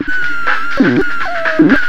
FXBEAT06-R.wav